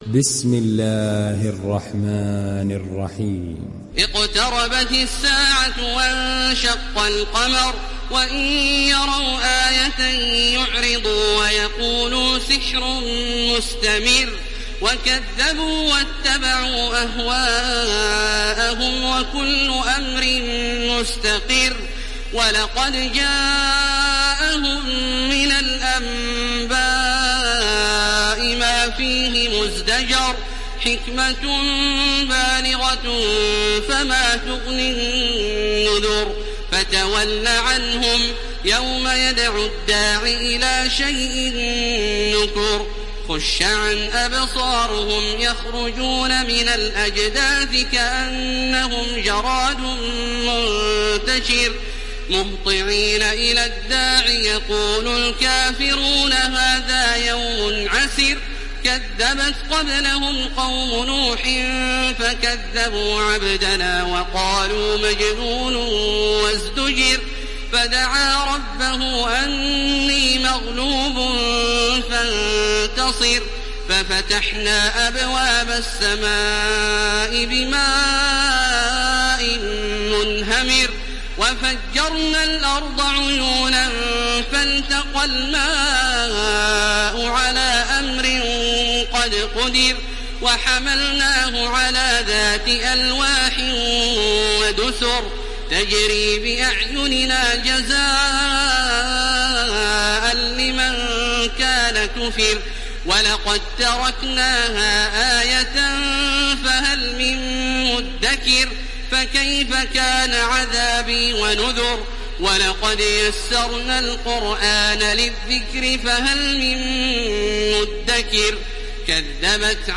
دانلود سوره القمر تراويح الحرم المكي 1430